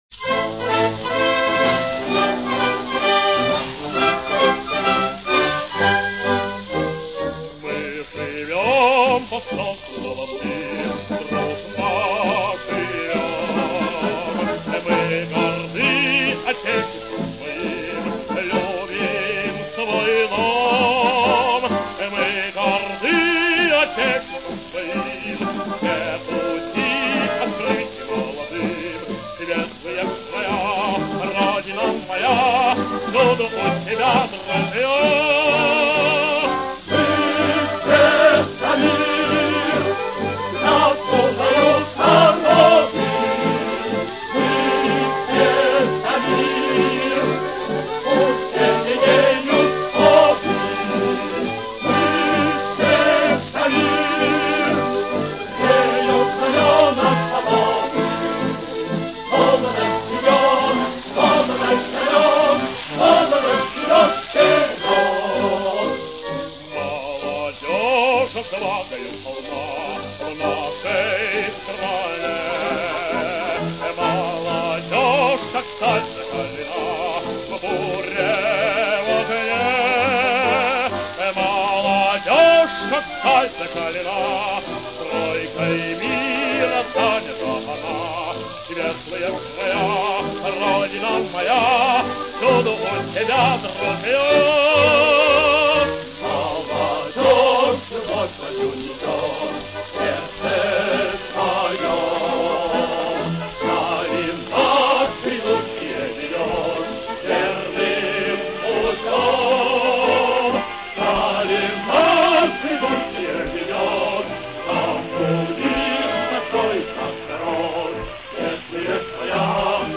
Марши
с хором и оркестром